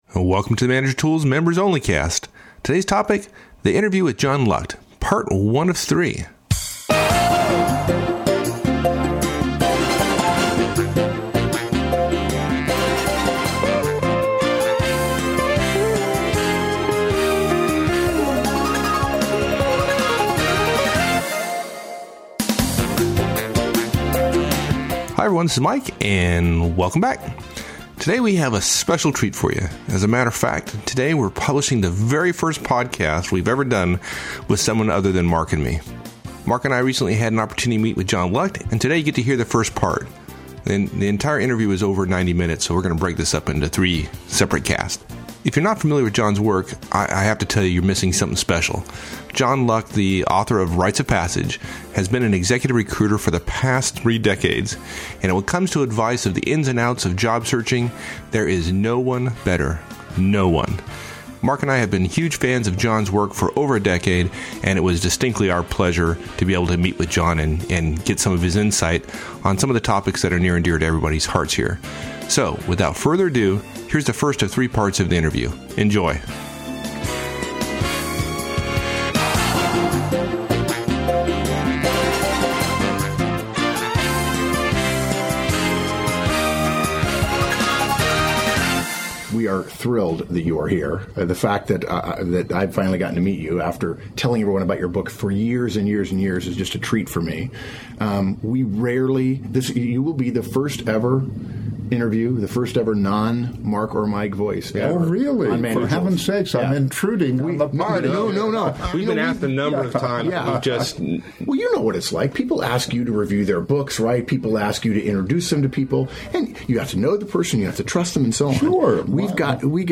In this show, you get to hear the first part of the 90 minute interview.